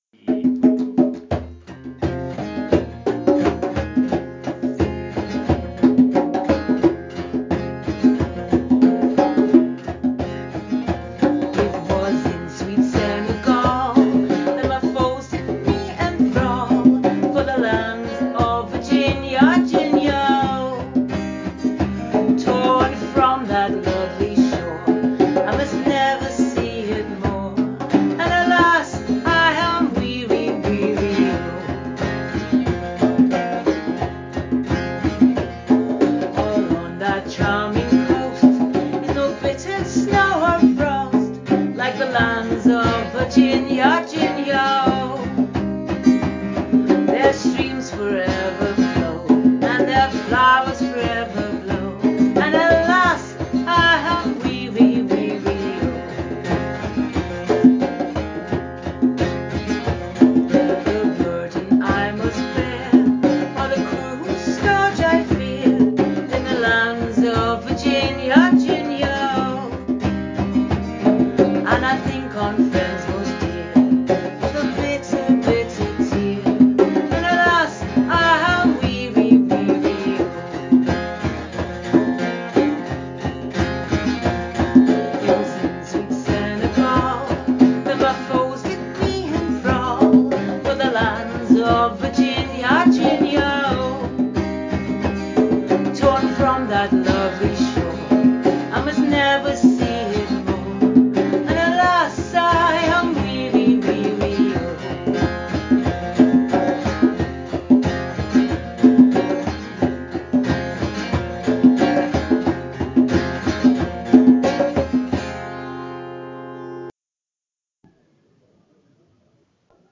Interview
on the Scottish slave trade taboo(podcast with songs)